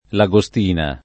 Lagostina [ la g o S t & na ] cogn.